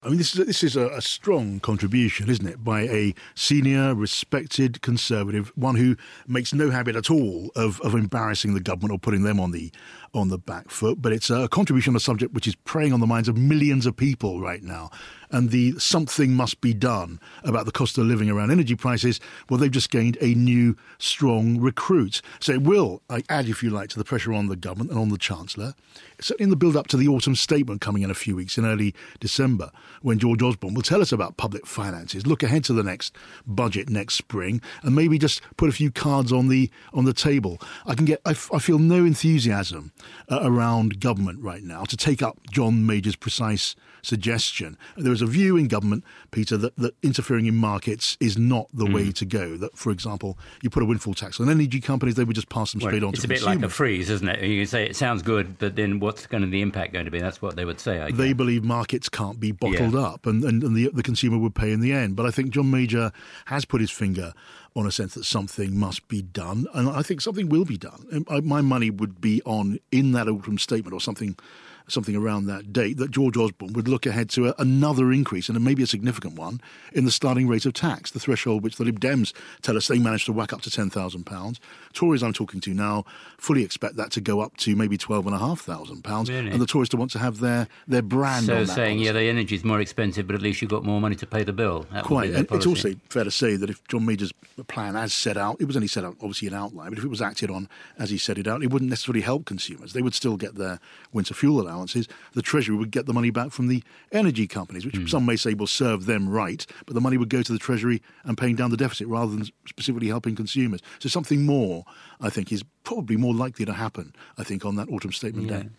John Pienaar analyses John Major's intervention over energy prices on 5 live Drive.